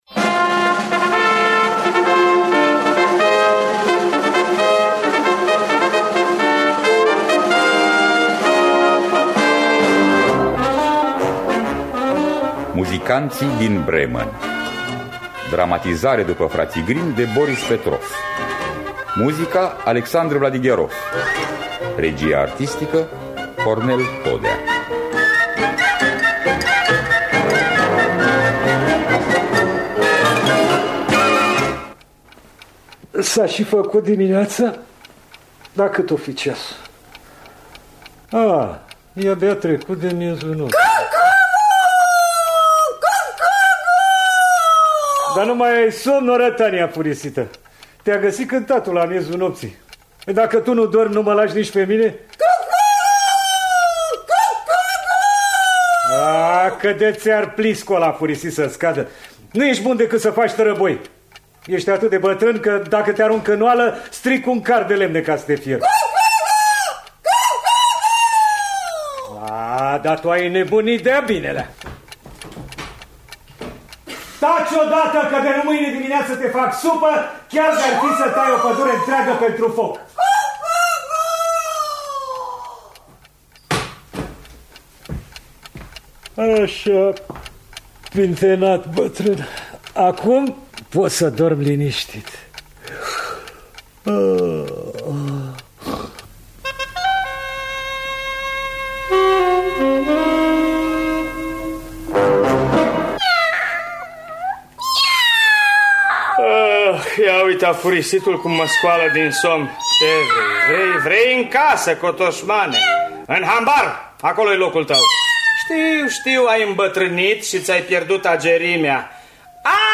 Muzicanţii din Bremen de Fraţii Grimm – Teatru Radiofonic Online